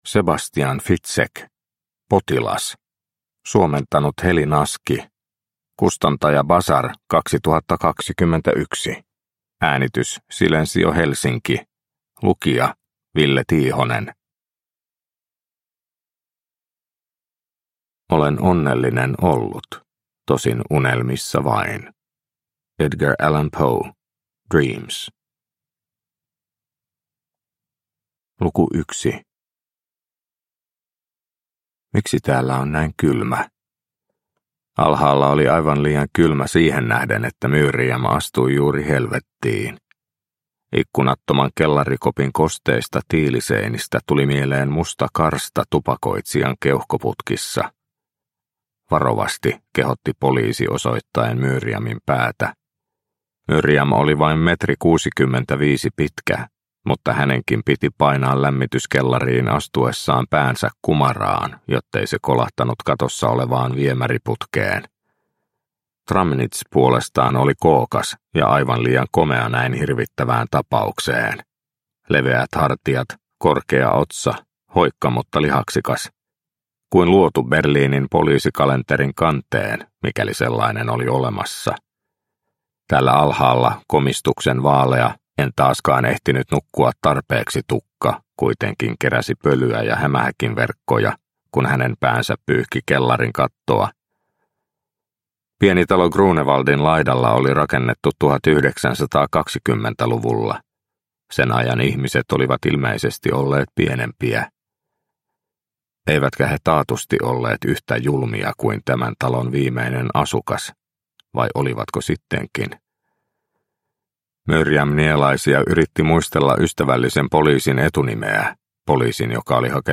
Potilas – Ljudbok – Laddas ner